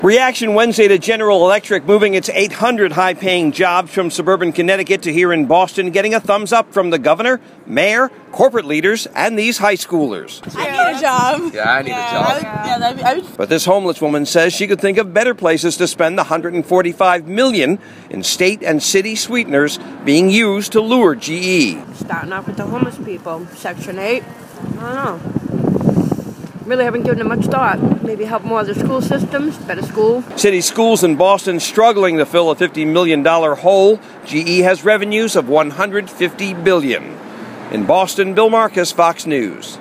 THIS WOMAN IN BOSTON, WHO WAS WEARING A BERNIE SANDERS BUTTON, WONDERS OUT LOUD ABOUT THE PROSPECT OF PAYING OUT $145 MILLION IN STATE AND CITY FUNDS TO LURE G-E. SHE DECIDES IT’S NOT A GOOD IDEA.